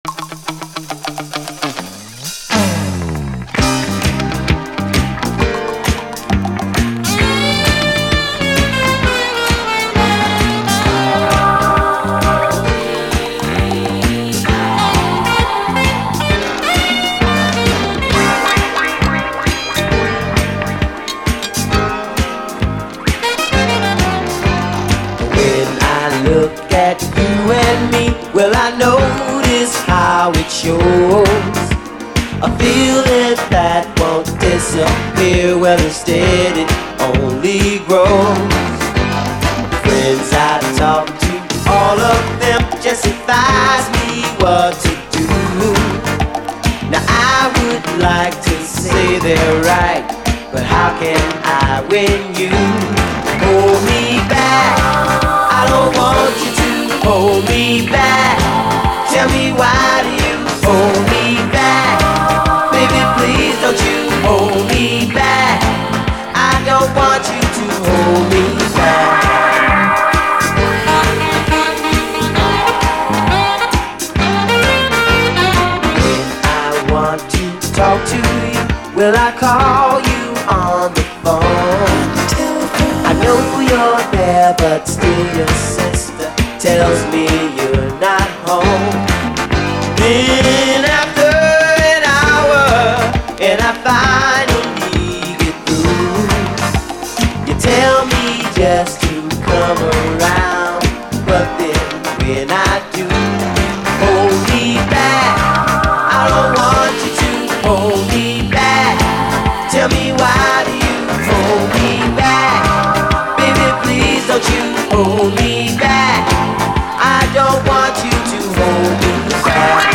REGGAE
独特のヒンヤリした質感、悩ましいムードのオランダ産アーバン・メロウ・レゲエ！